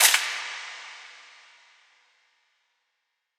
MZ FX [Drill Shot].wav